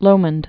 (lōmənd), Loch